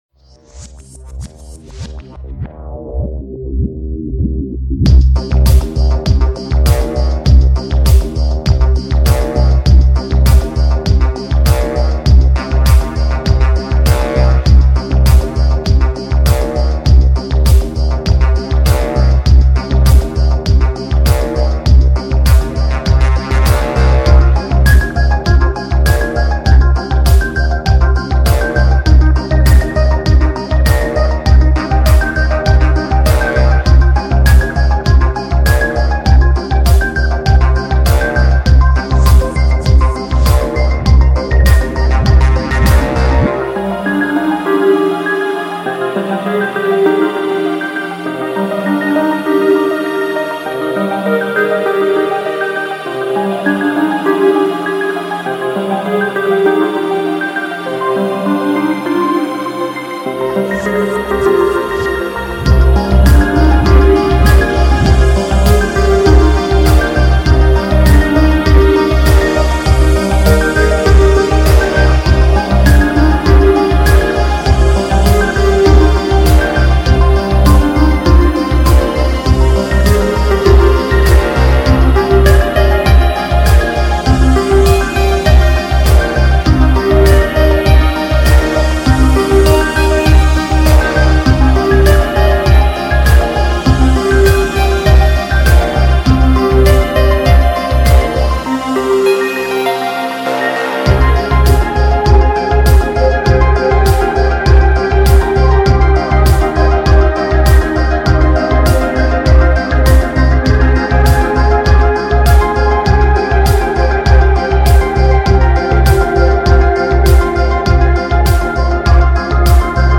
音乐类别: 氛围电音
为低音质MP3